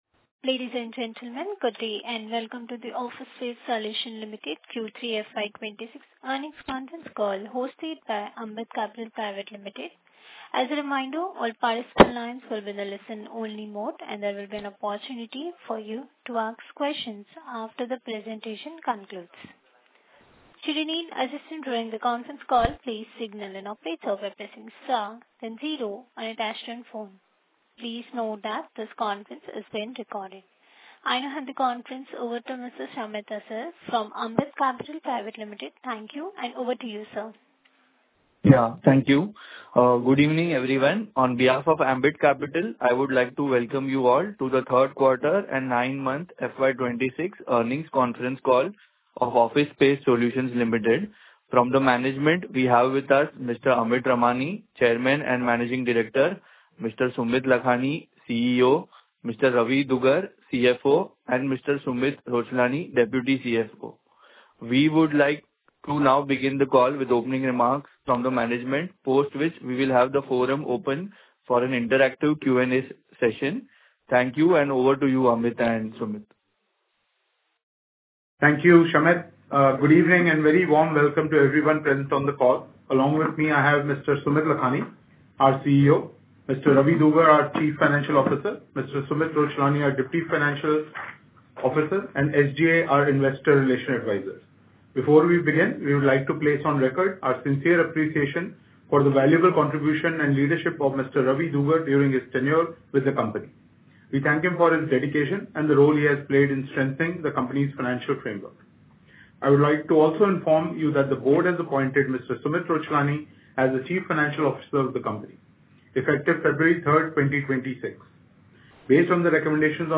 Recording Earning Call 12.02.2025